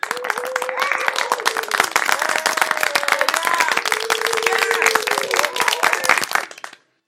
群众鼓掌
描述：大人群鼓掌和wohoooing。录制在佳能eos 5d Mark II原生音色上。
标签： 礼堂 拍手 欢呼 欢呼 受众人群 掌声
声道立体声